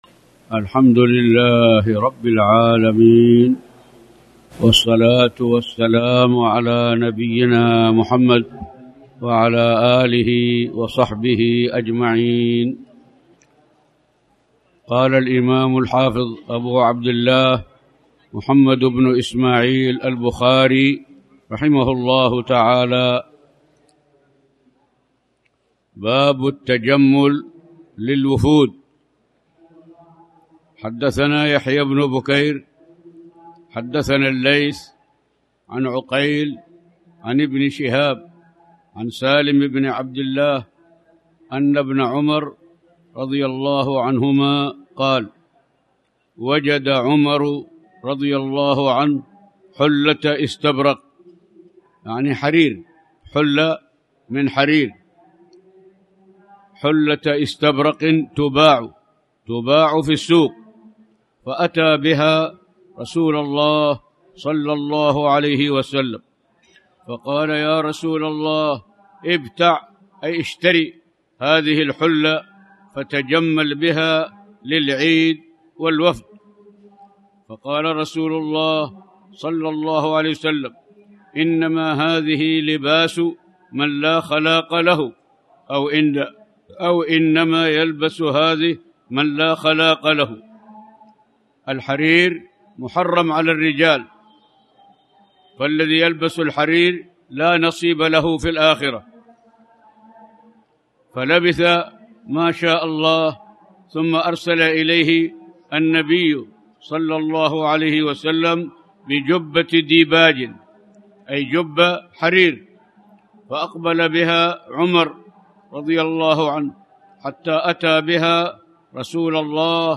تاريخ النشر ٧ محرم ١٤٣٩ هـ المكان: المسجد الحرام الشيخ